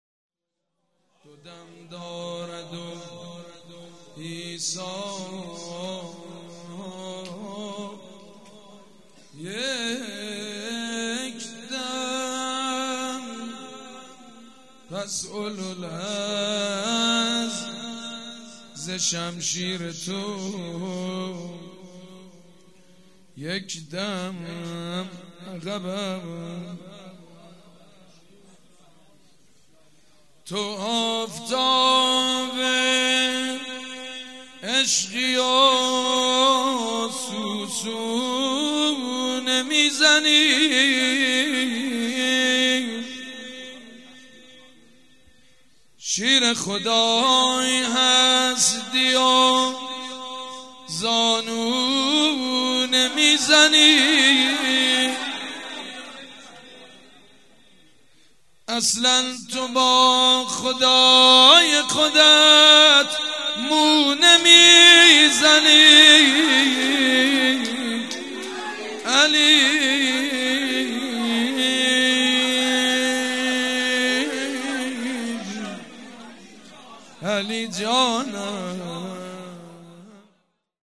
شب شهادت حضرت زينب(س)
شعر خوانی
شعر خوانی_تو آفتاب عشقی و سو سو نمیزنی.mp3